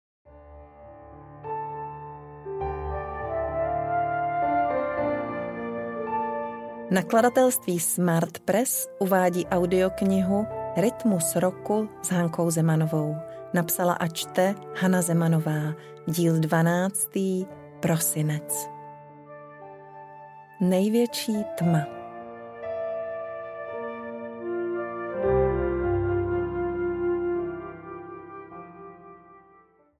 Ukázka z knihy